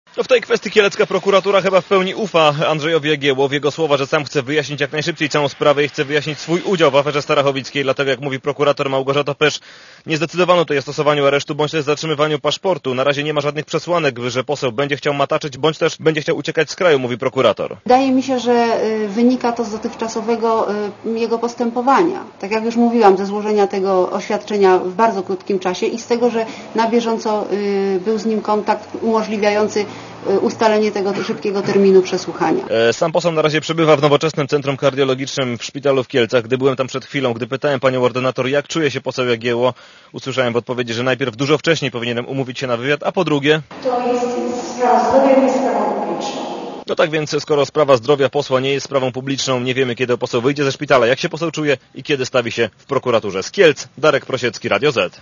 O tym reporter Radia Zet (216Kb)